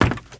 STEPS Wood, Creaky, Run 08.wav